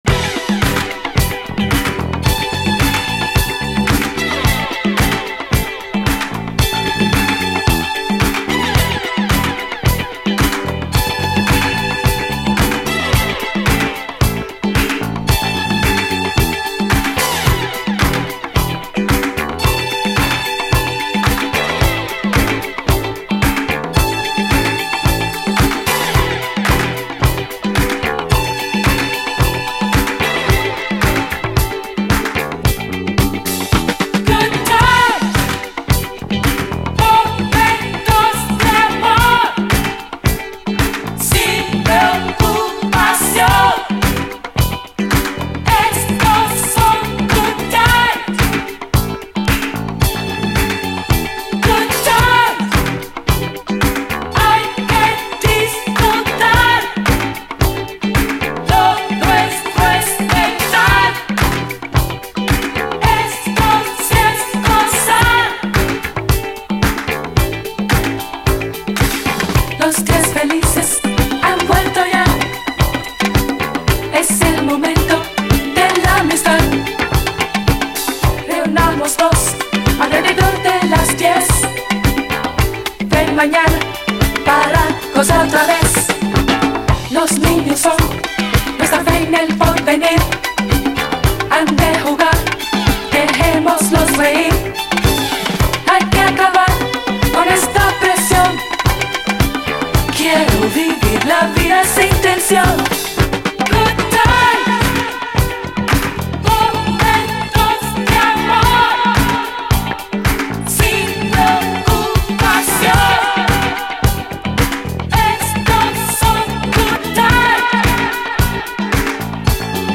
SOUL, 70's～ SOUL, DISCO
ダビーなディレイ処理がカッコいい名作！
ディスコ・ブレイク以降、より本来のチャランガっぽさが出てくるパーカッシヴな後半もよいです。